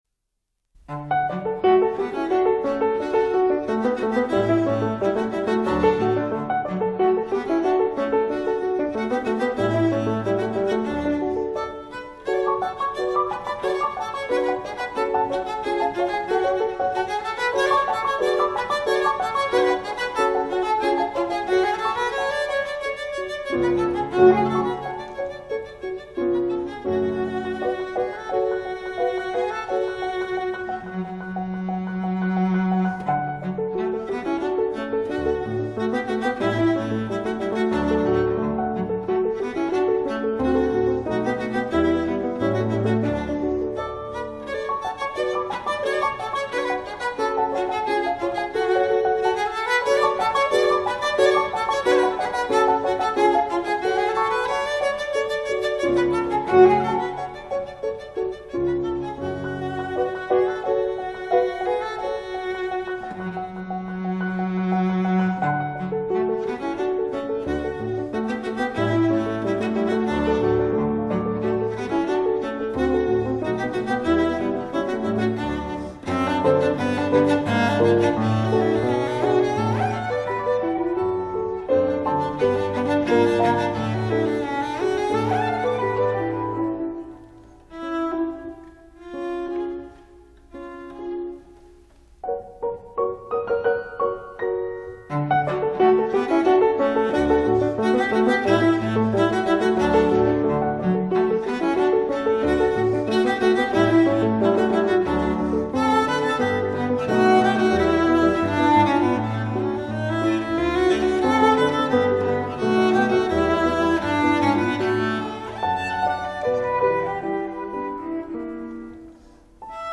D. Popper - Gavott för violoncell och piano nº2 D-dur op. 23